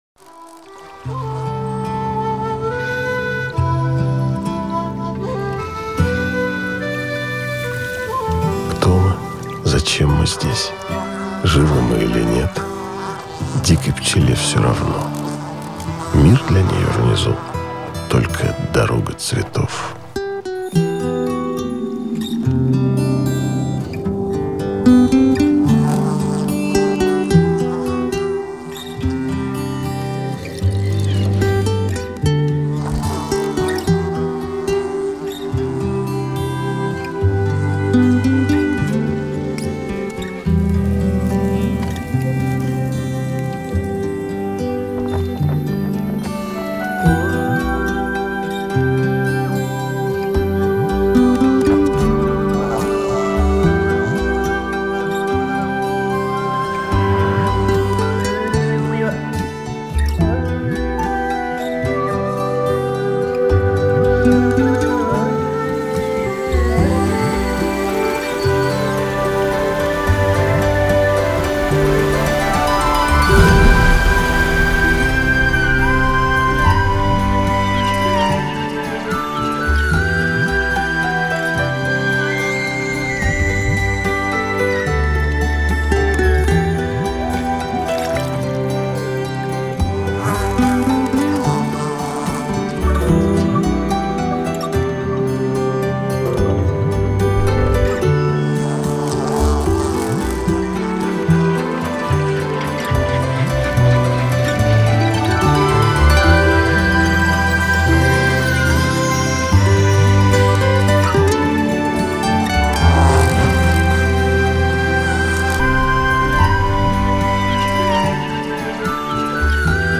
• Category: 3d sound